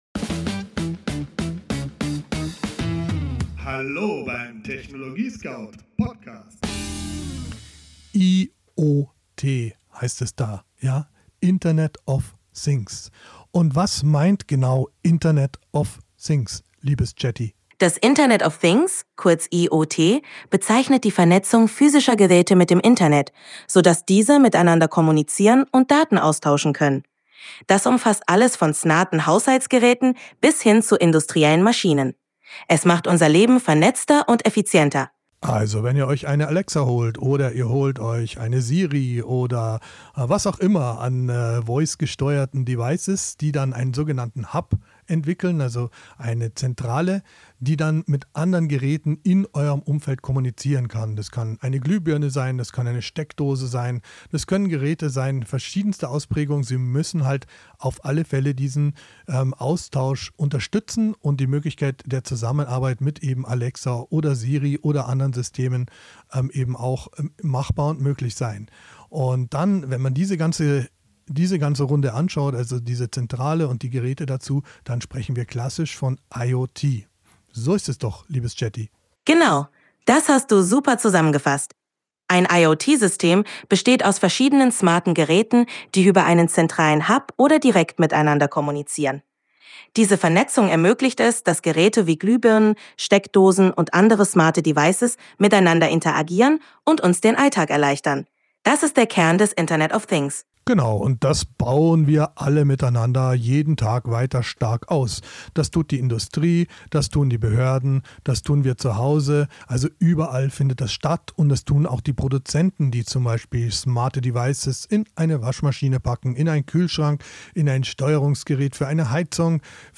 Dialog im TechnologieScout-Studio